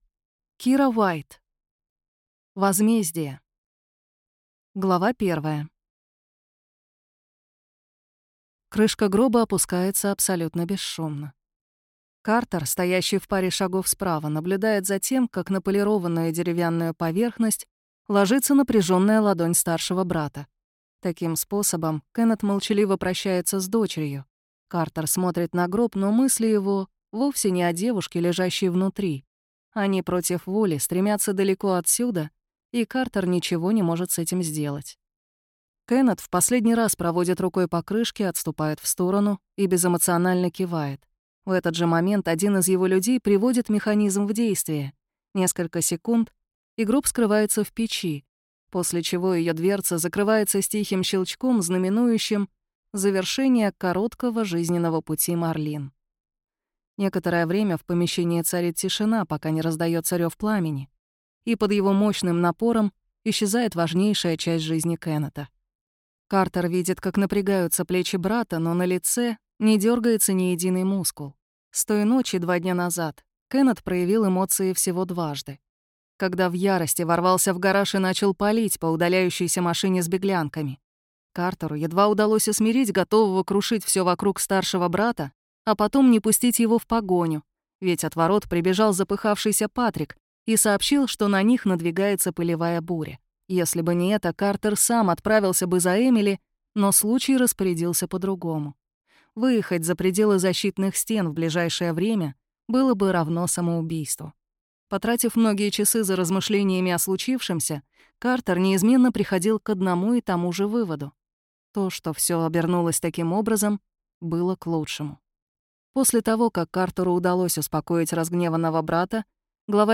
Аудиокнига Возмездие | Библиотека аудиокниг